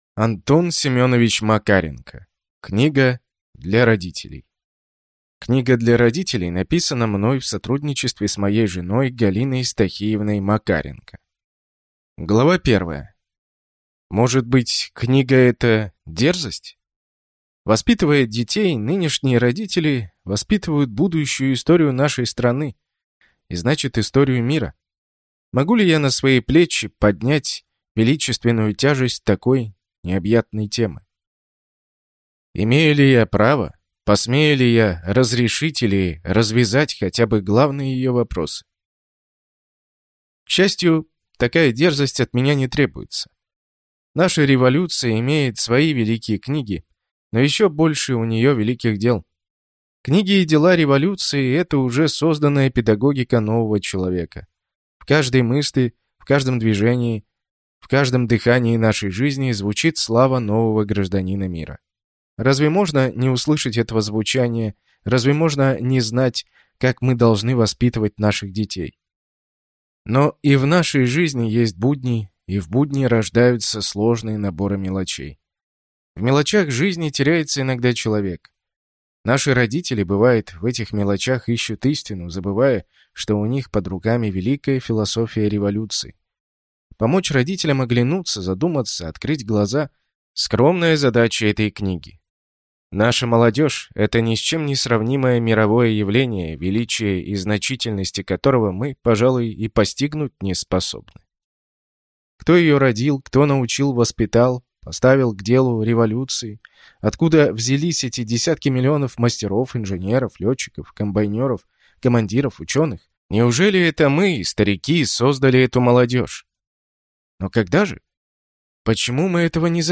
Аудиокнига Книга для родителей | Библиотека аудиокниг